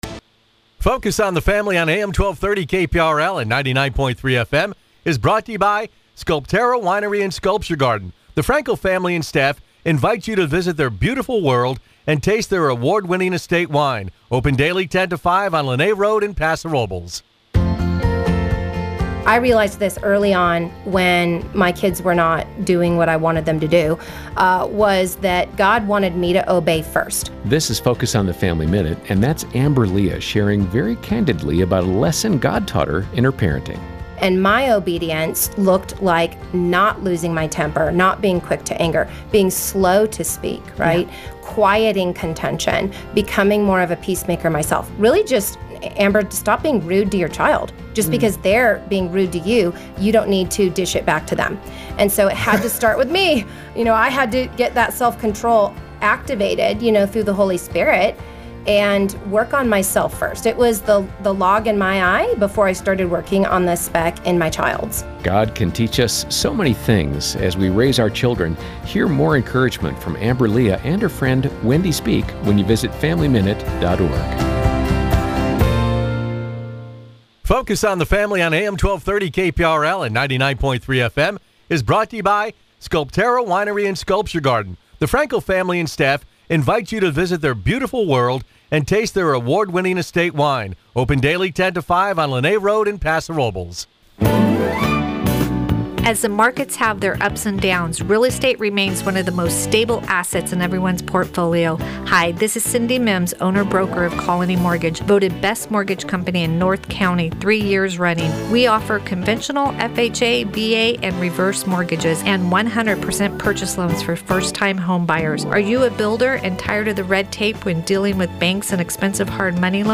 Tune in to the longest running talk show on the Central Coast – now in its sixth decade.